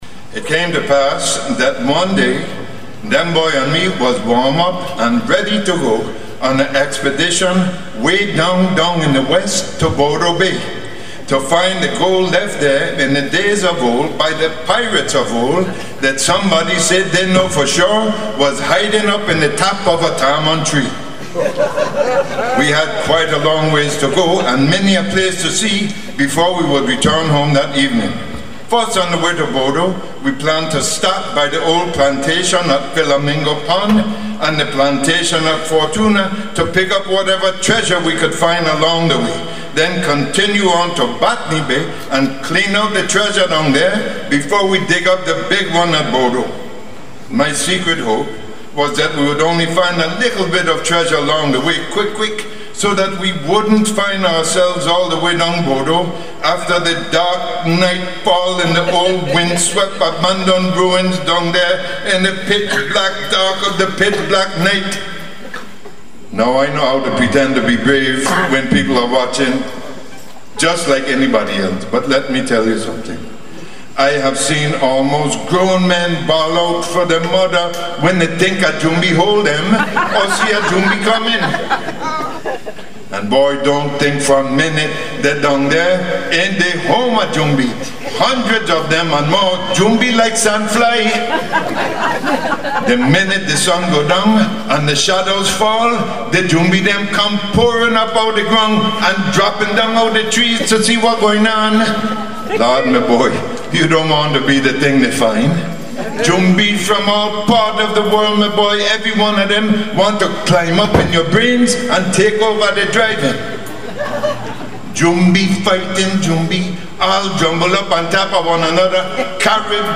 Book 4. Buckra De Paehae, Captain Hookfoot! Live At The J. Antonio Jarvis Museum, Pollyburg Hill, St.Thomas, Virgin Islands.
This one is a  live, “in performance” recording  of “Captain Hookfoot” recorded at the Jarvis Museum, on Pollyburg Hill, in St. Thomas Virgin Islands.